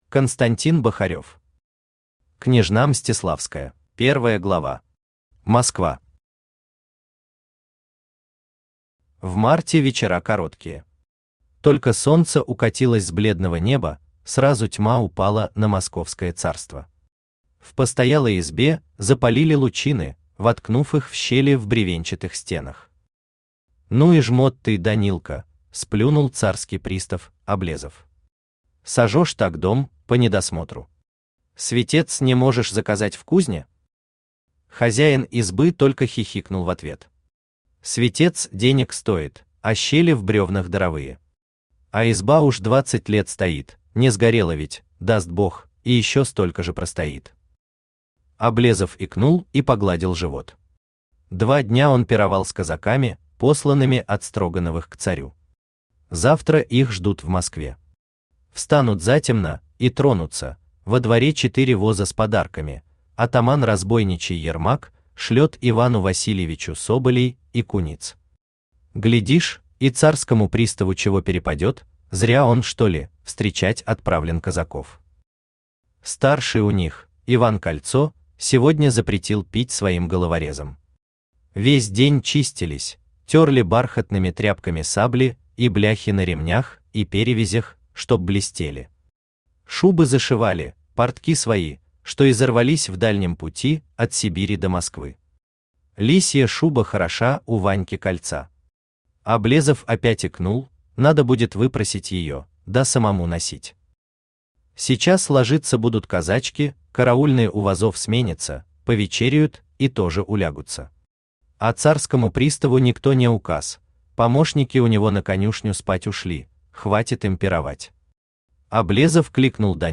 Аудиокнига Княжна Мстиславская | Библиотека аудиокниг
Aудиокнига Княжна Мстиславская Автор Константин Павлович Бахарев Читает аудиокнигу Авточтец ЛитРес.